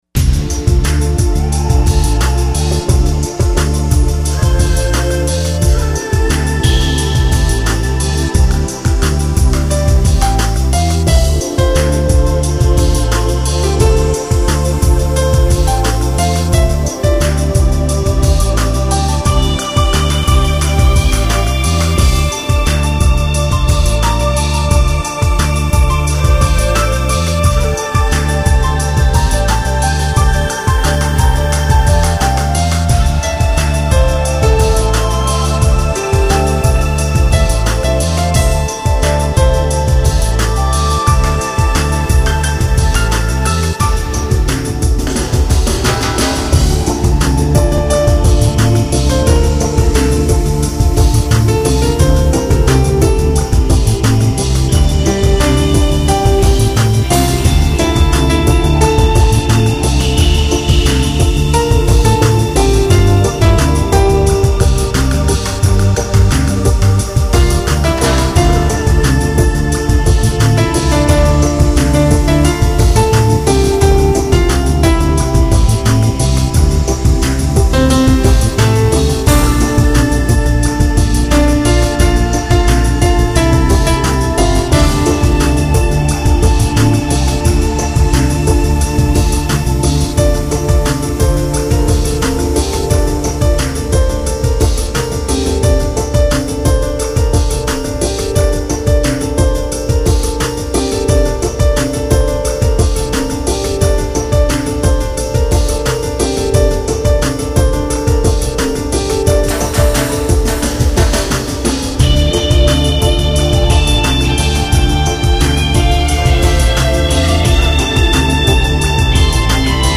配器丰富、考究，旋律跌宕动跃
尤其是梦幻钢琴曲调的插入，更使音乐高雅脱俗、灵动致远。